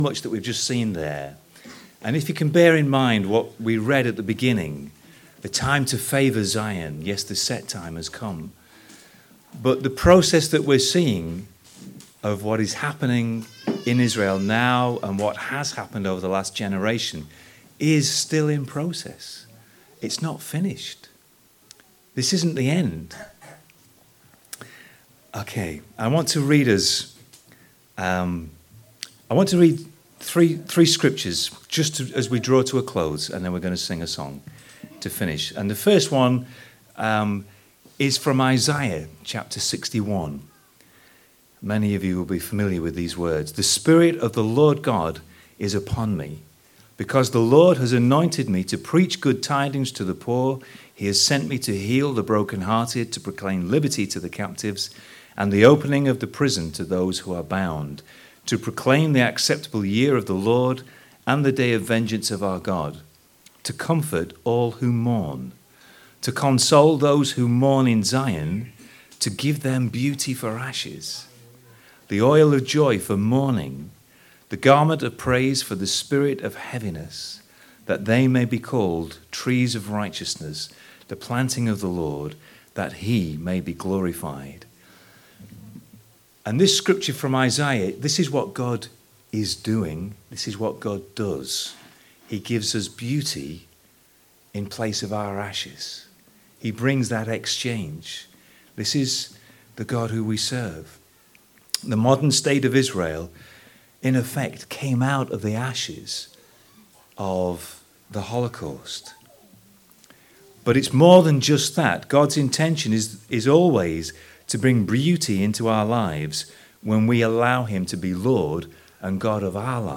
for our commemoration of Holocaust Memorial Day.